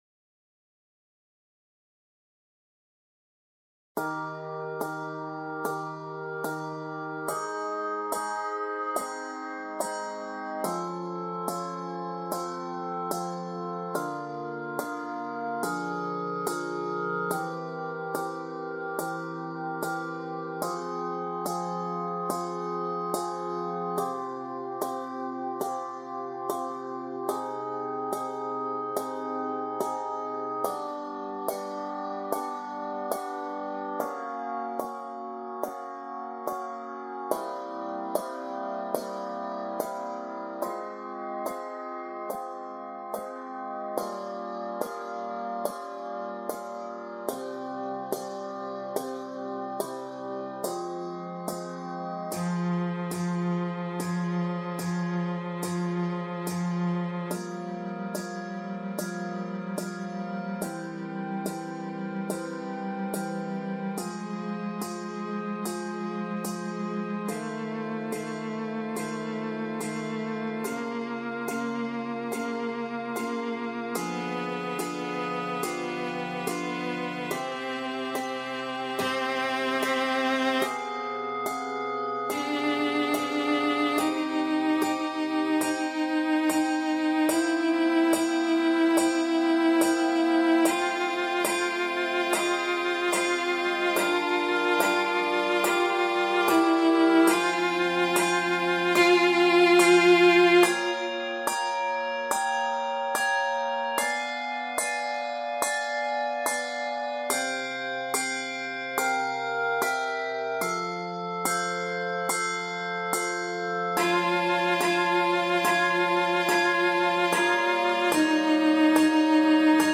haunting aria